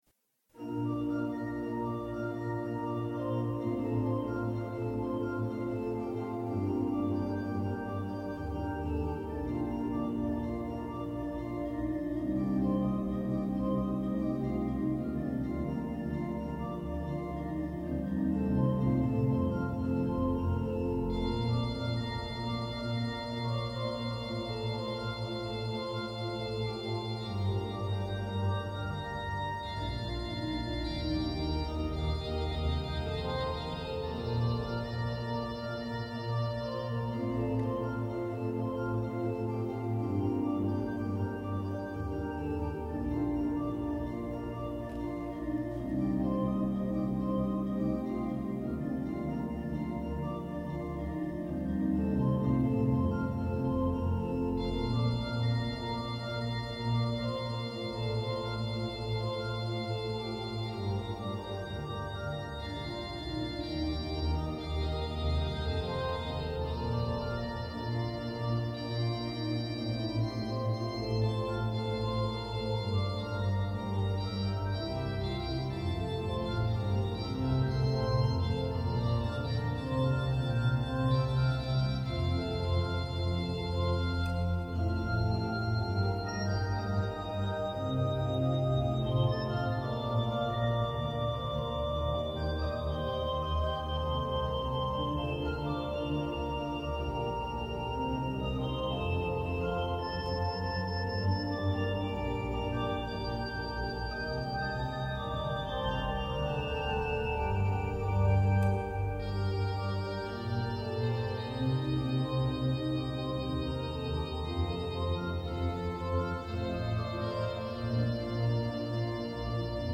Concierto de Otoño a la Luz de las Vels
al Órgano Allen de la S.I. Catedral Metropolitana de Valladolid.
Tomaso Giovanni Albinoni compositor italiano del Barroco